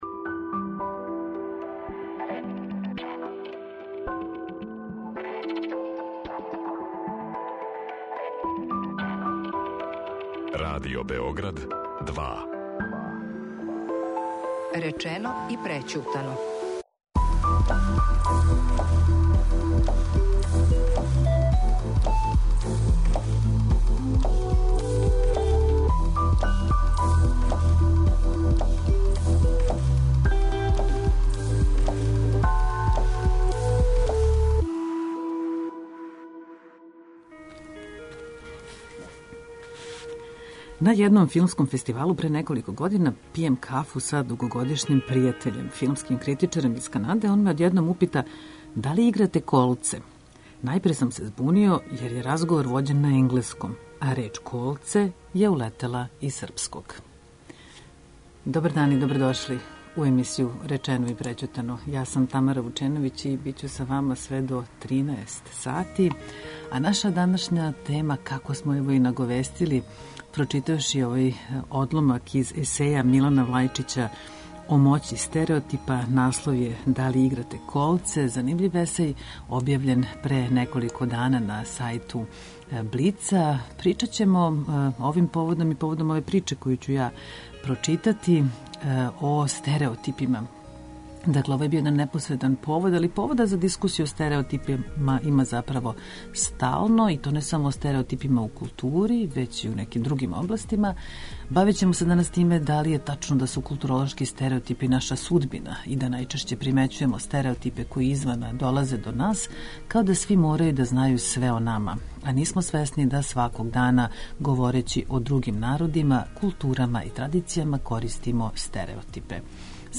социолог културе и новинар
музиколошкиња и социолошкиња.